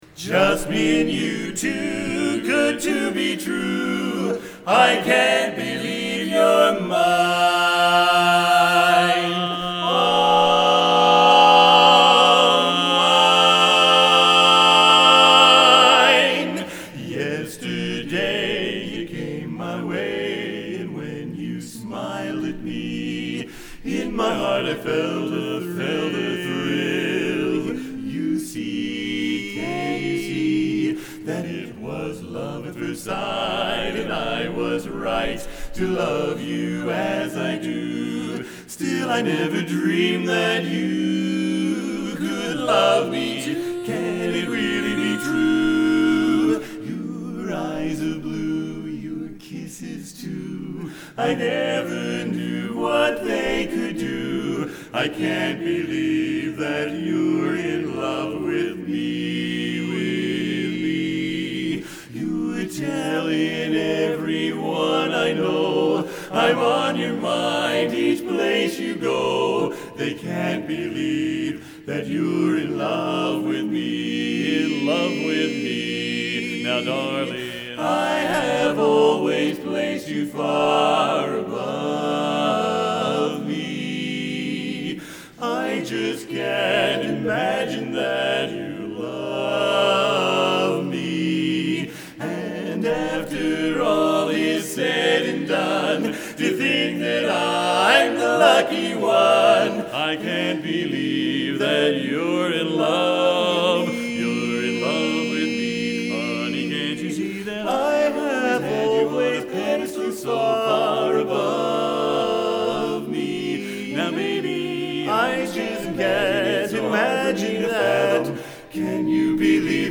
Sample Songs: